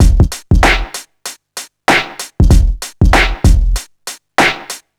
Free breakbeat sample - kick tuned to the F note. Loudest frequency: 1890Hz
• 96 Bpm Breakbeat F Key.wav
96-bpm-breakbeat-f-key-Mwn.wav